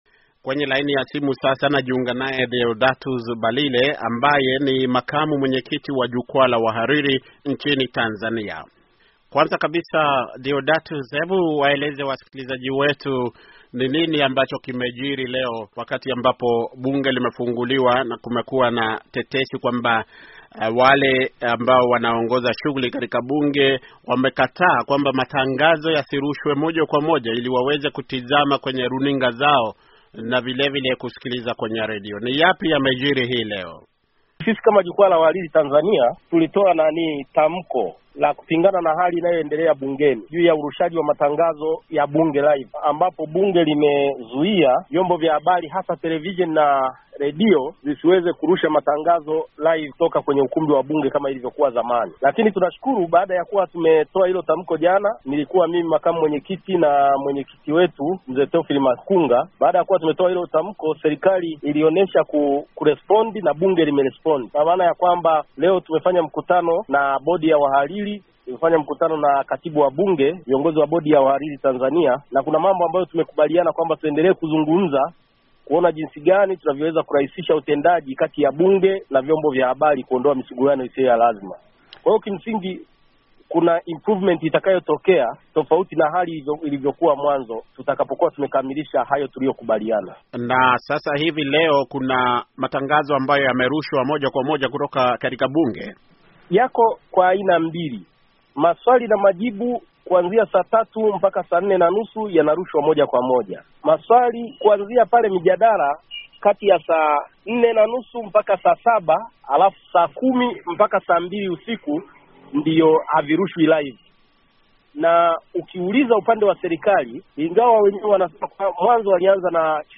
akizingumza na Idhaa ya Kiswahili